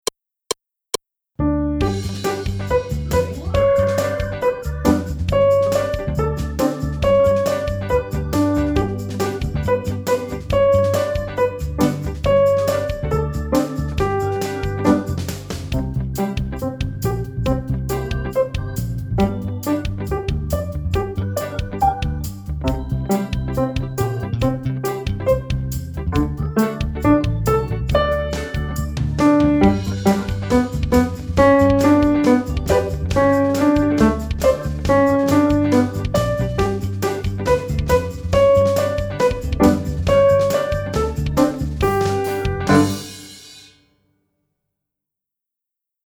합주